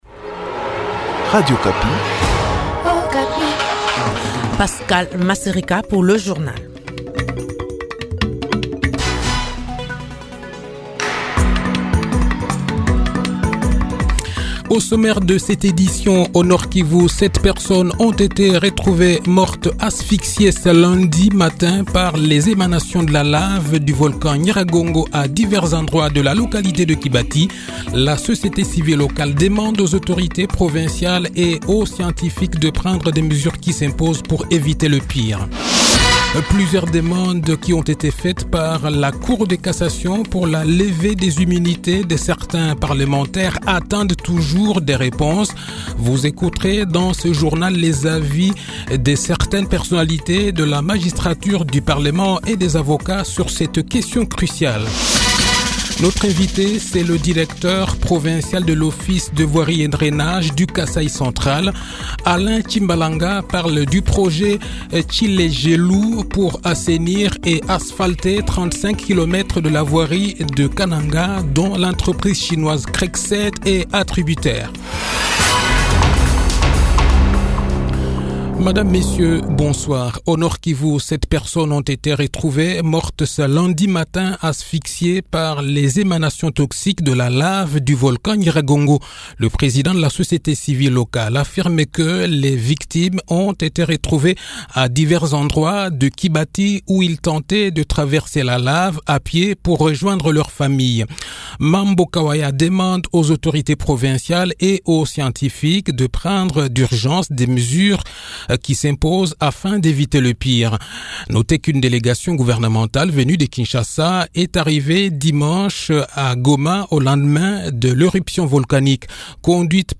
Le journal-Français-Soir